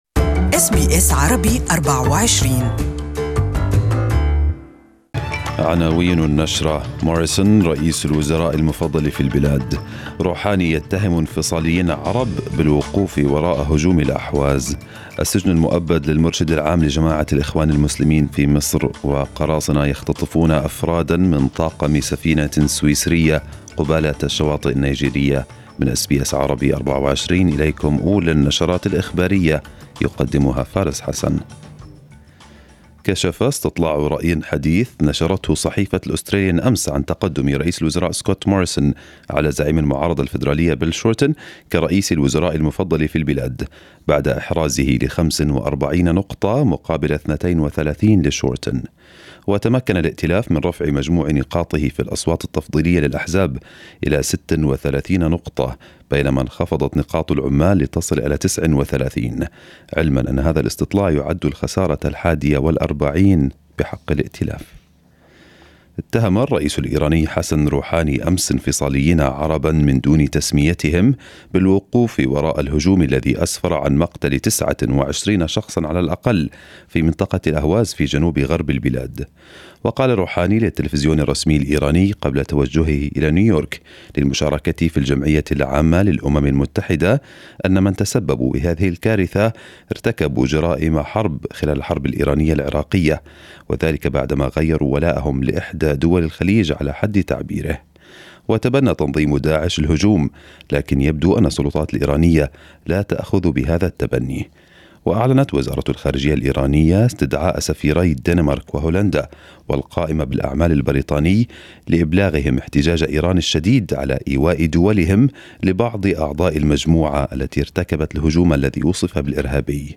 First news bulletin of the day